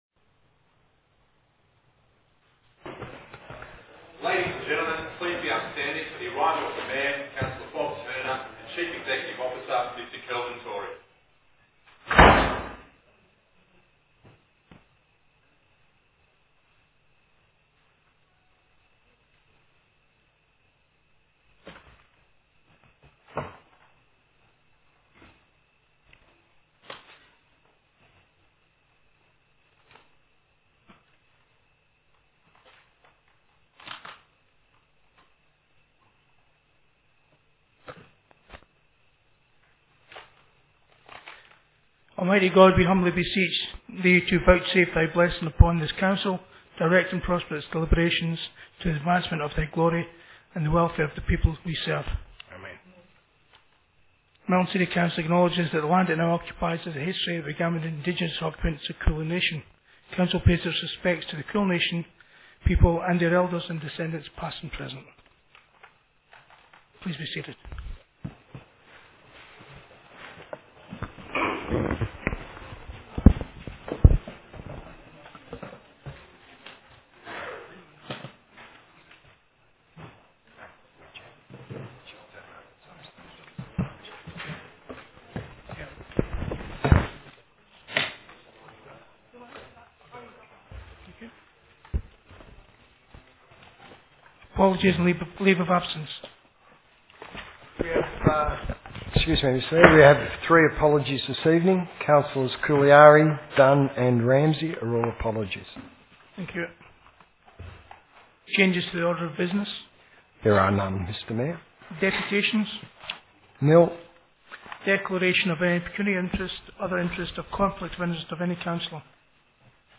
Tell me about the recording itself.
26 August 2014 - Ordinary Council Meeting